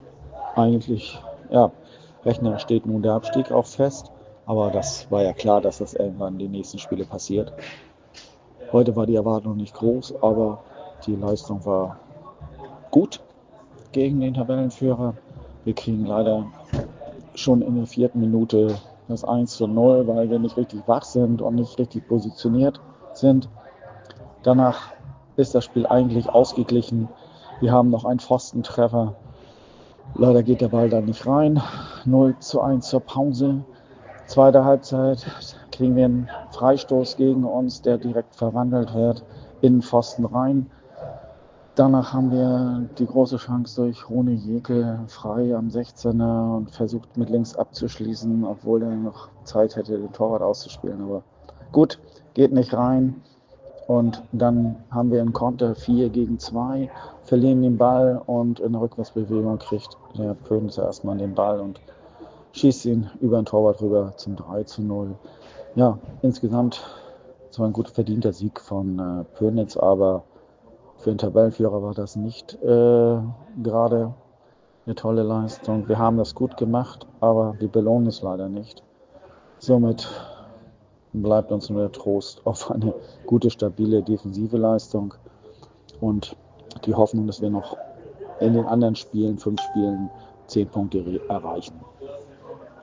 Stimme zum Spiel